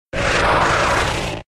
Cri de Kadabra K.O. dans Pokémon X et Y.